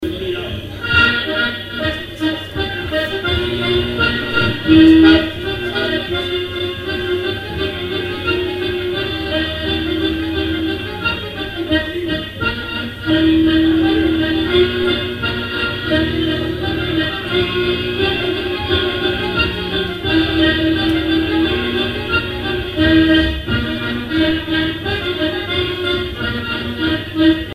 Mémoires et Patrimoines vivants - RaddO est une base de données d'archives iconographiques et sonores.
danse : paligourdine
airs pour animer un bal
Pièce musicale inédite